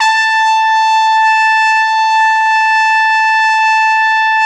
Index of /90_sSampleCDs/Best Service ProSamples vol.20 - Orchestral Brass [AKAI] 1CD/Partition A/VOLUME 002